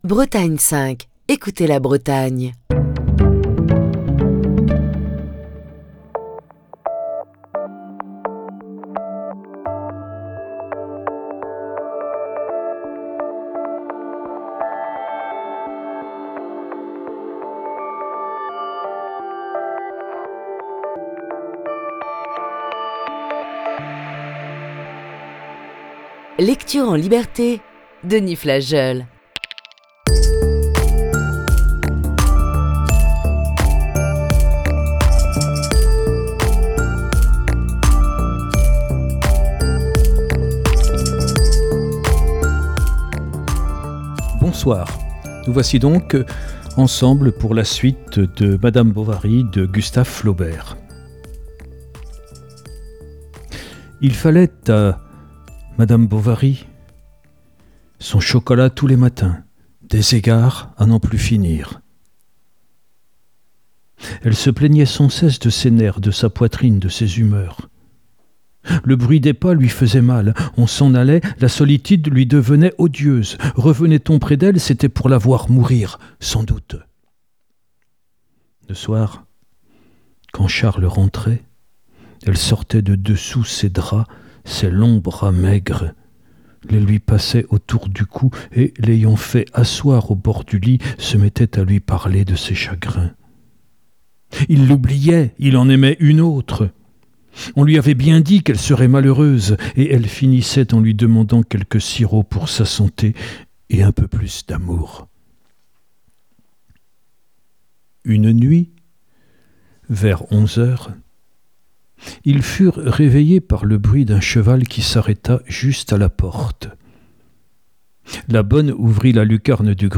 lit un grand classique de la littérature française, Madame Bovary de Gustave Flaubert. Ce soir, voici la deuxième partie de ce récit.